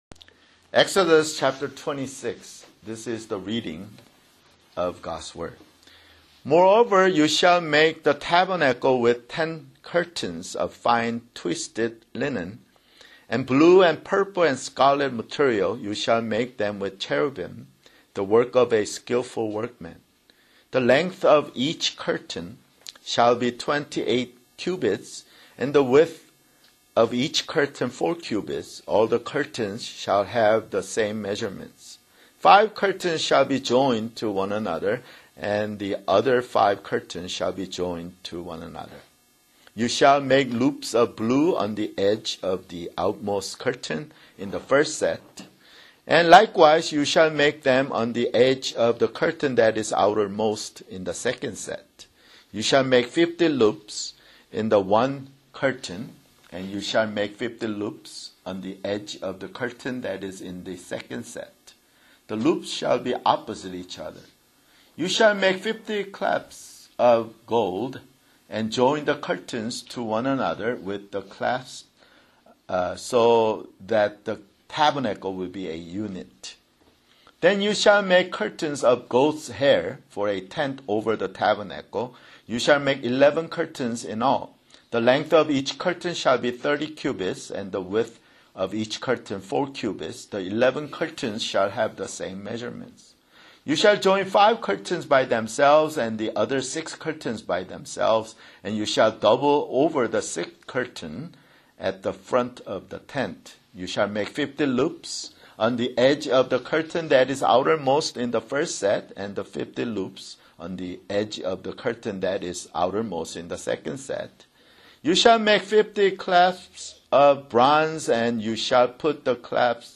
[Sermon] Exodus (77)